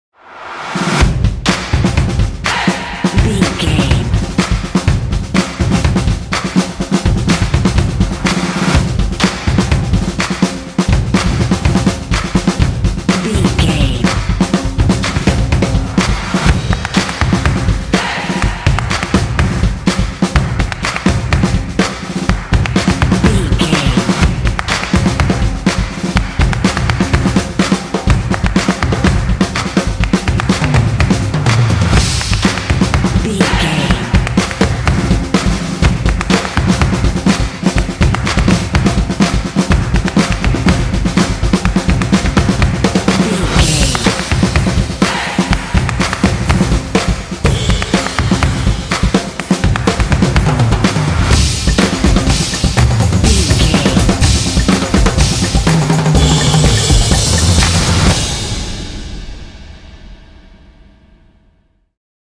This epic drumline will pump you up for some intense action.
Epic / Action
Atonal
driving
determined
drums
percussion
drumline